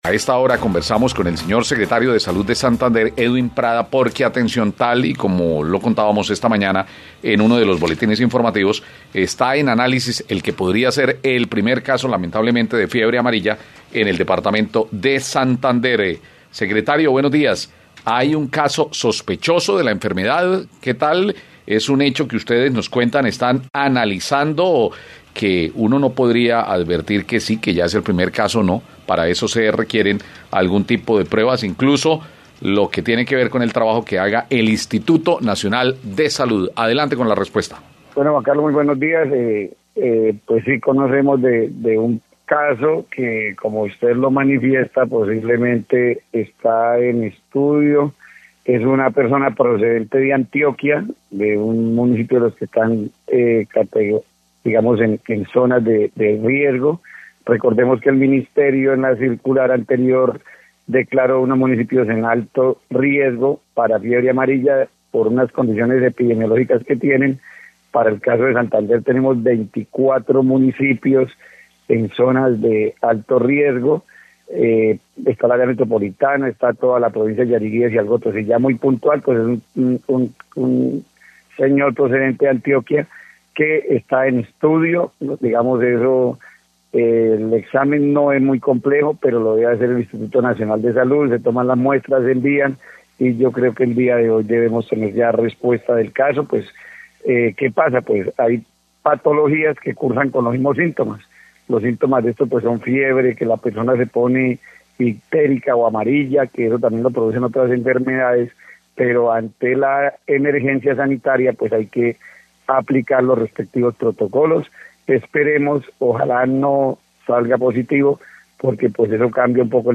Edwin Prada, Secretario de Salud de Santander